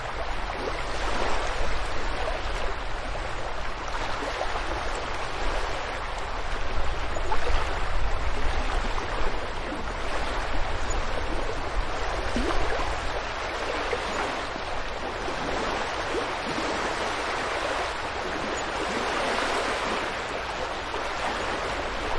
海 " 海浪
描述：在Esbjerg附近的Ho Bugt，短暂而活泼。并且，正如思想总是......风在相当短的时间内变得更强，甚至挡风玻璃也无法应对。 MKH 30/40，Mixpre，SD702。
Tag: 沙滩 海浪 浩bugt 海边 丹麦 现场录音